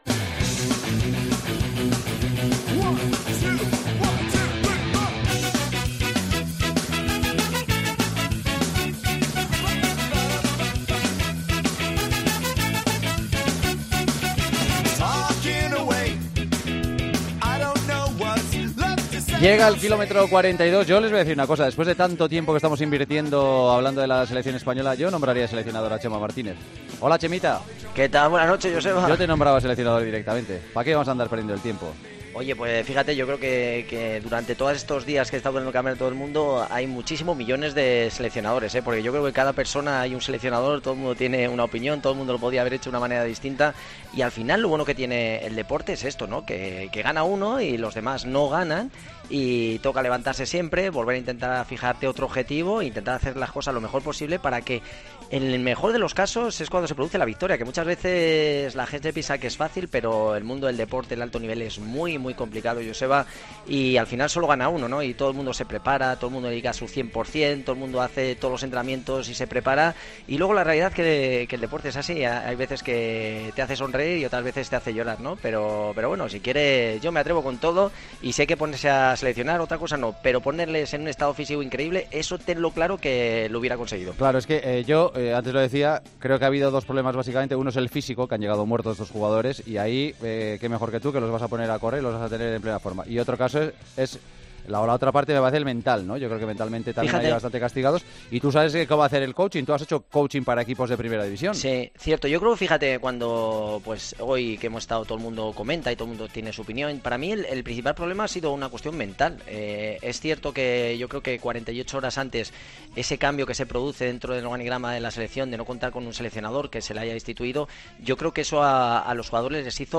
AUDIO: Con Chema Martínez, la sección de running en El Partidazo de COPE.